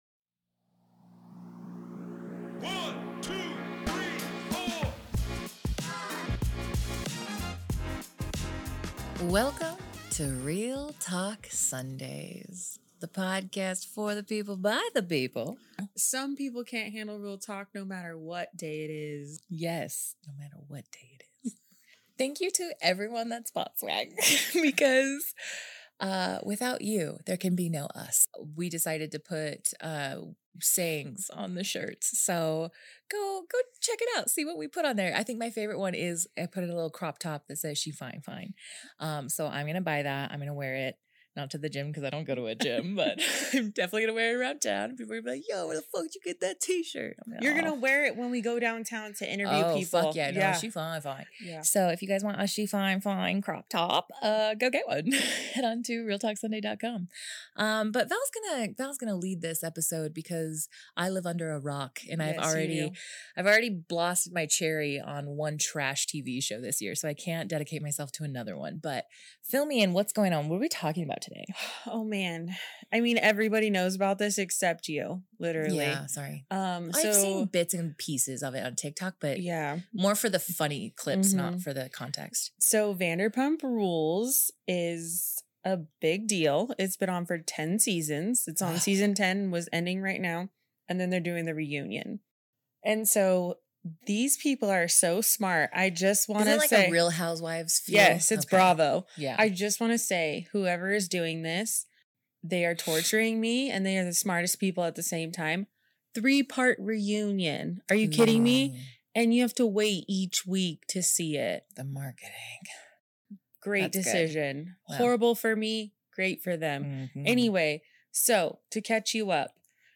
We've also got a special guest - a brave soul who's been cheated on - to tell us all about their experience.